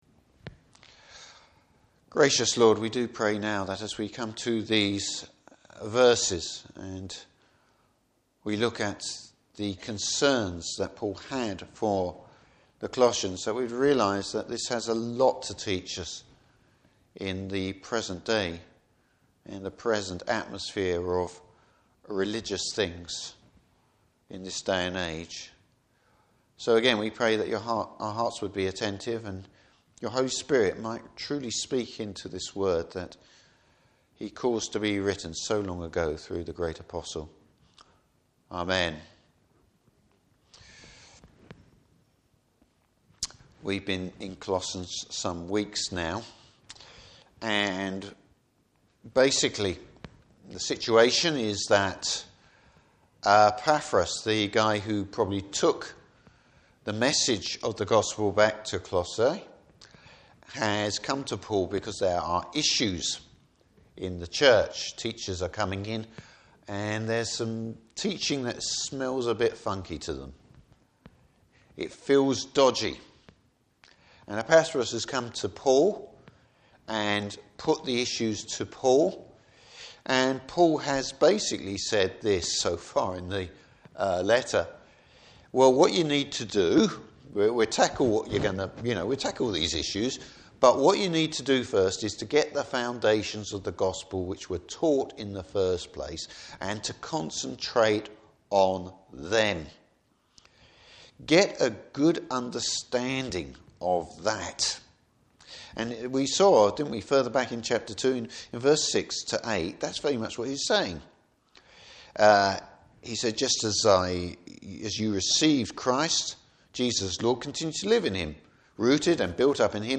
Service Type: Morning Service Don’t confuse religion with the worship of Christ!